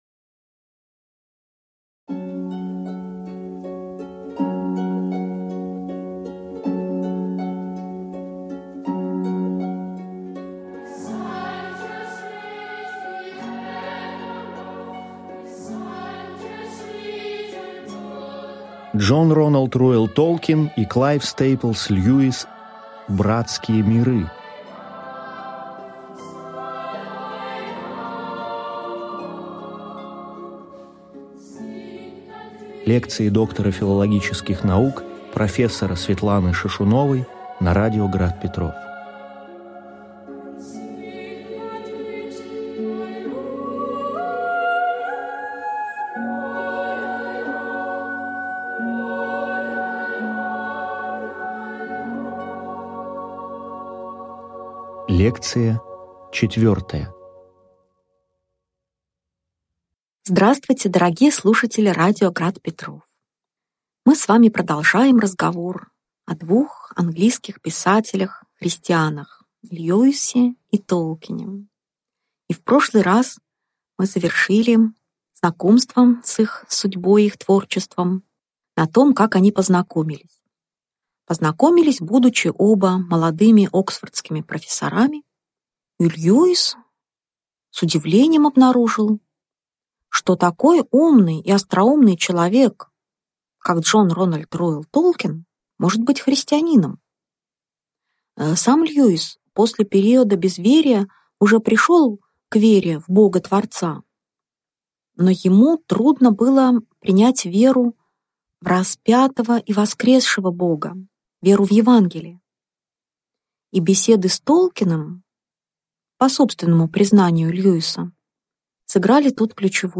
Аудиокнига Лекция 4. Место дружбы Дж.Р.Р.Толкина и К.С.Льюиса в их жизни | Библиотека аудиокниг